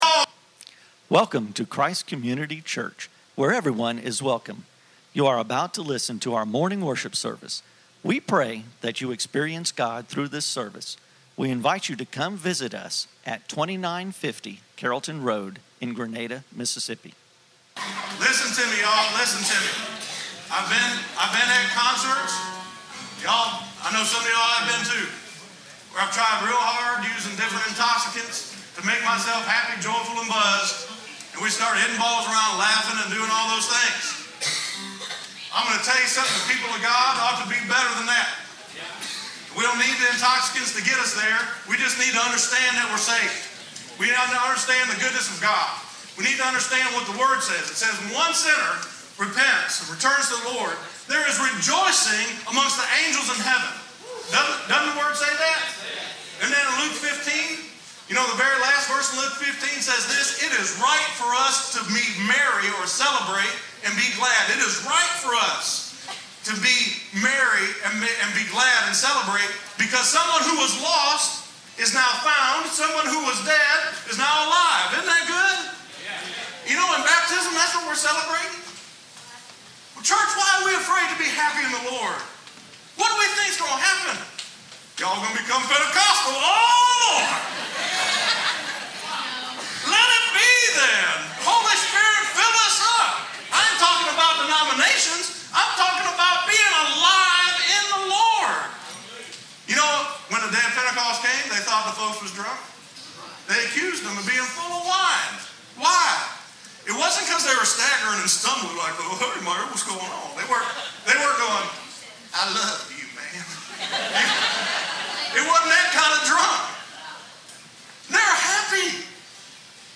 Baptism and Youth Led Worship Service